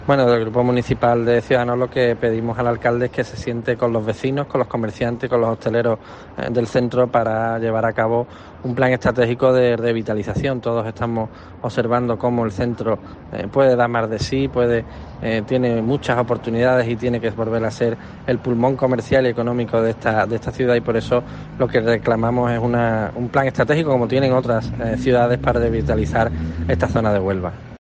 Guillermo García de Longoria, portavoz de Ciudadanos en el Ayuntamiento de Huelva